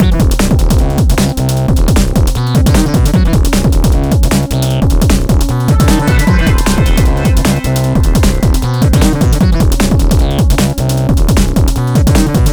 bust out the TR-606